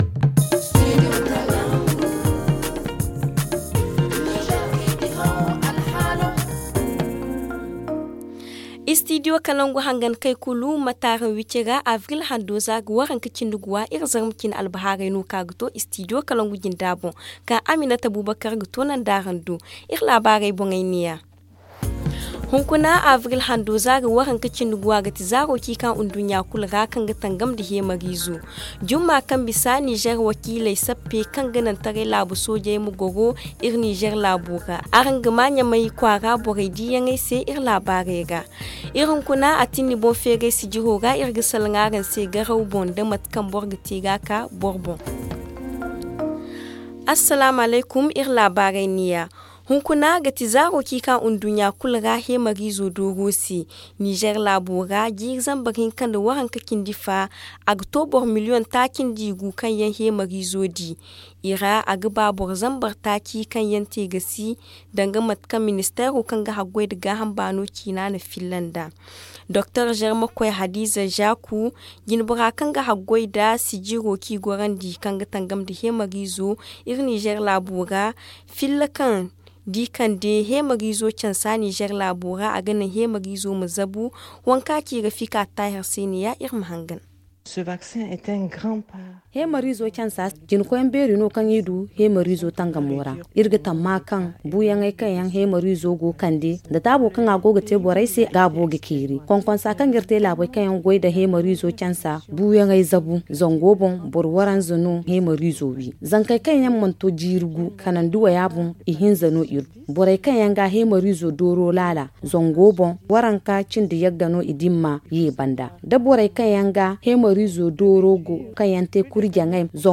Le journal du 25 avril 2022 - Studio Kalangou - Au rythme du Niger